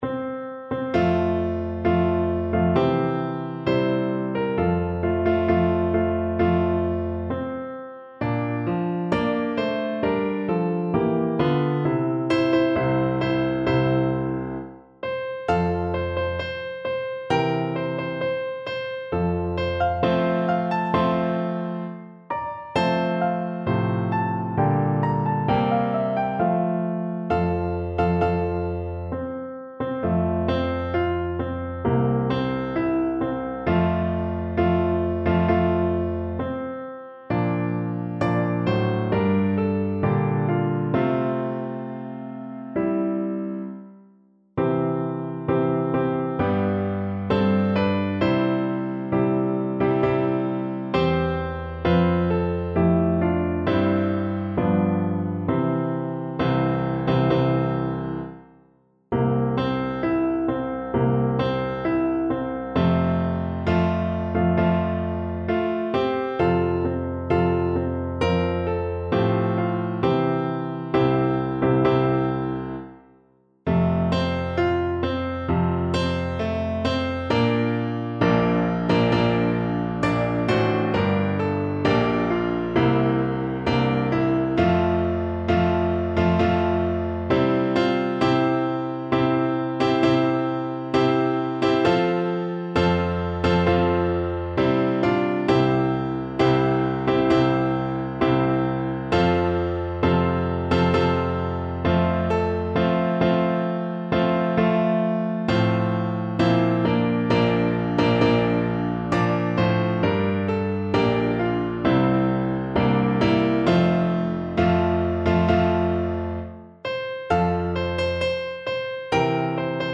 4/4 (View more 4/4 Music)
Maestoso
Voice  (View more Intermediate Voice Music)